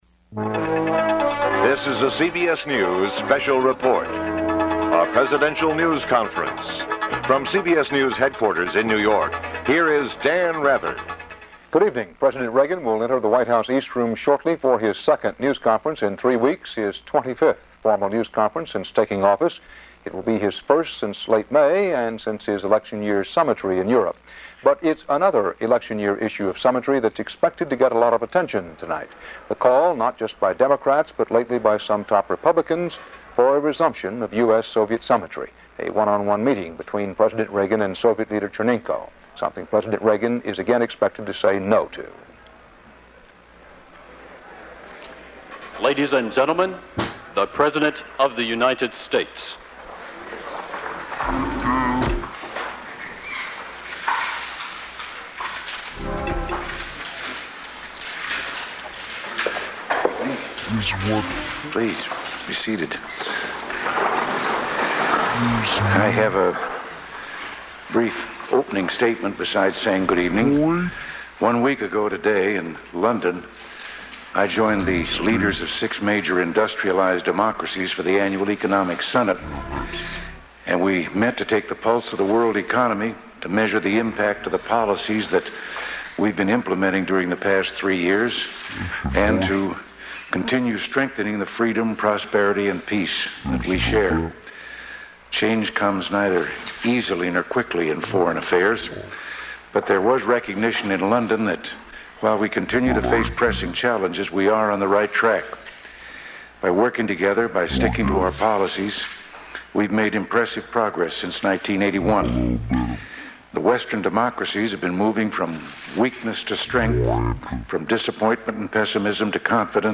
U.S. President Ronald Reagan delivers the twenty-fifth press conference of his presidency